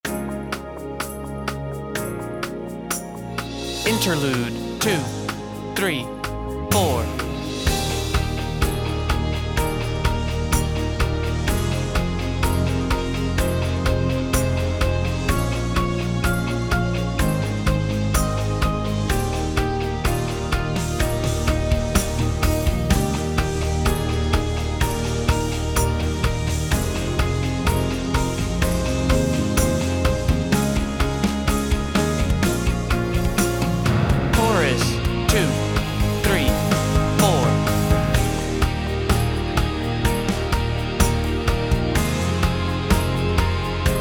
Key: A | Tempo: 126 BPM. https